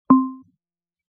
gong.mp3